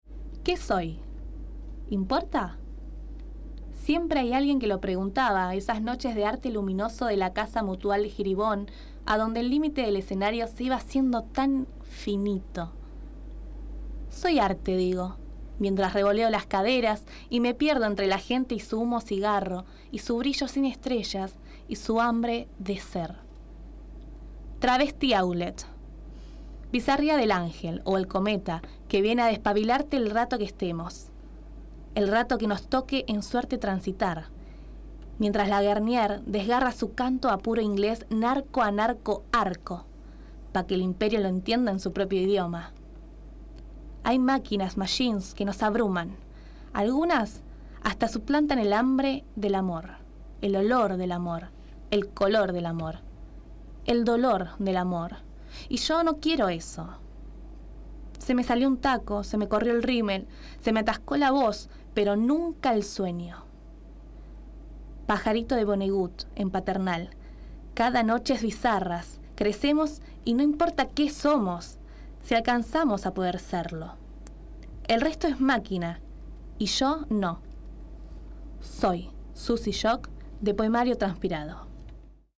Poesía